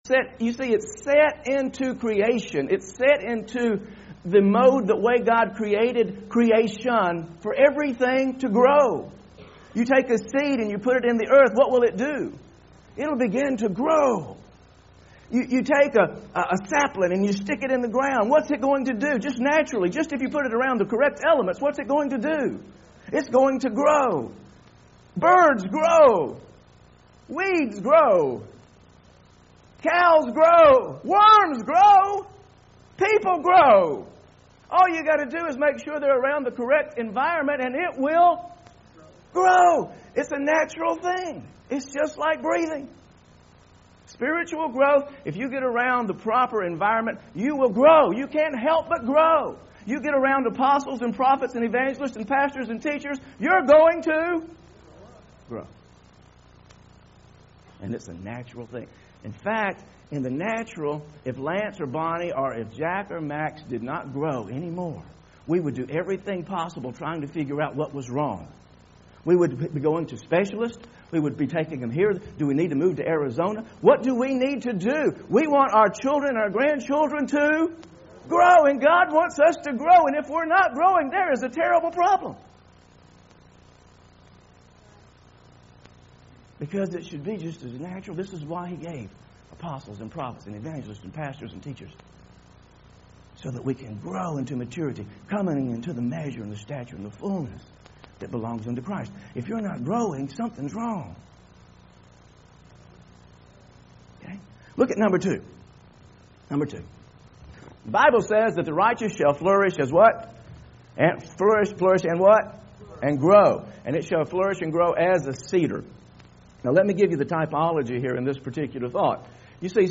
Church Growing sermon video audio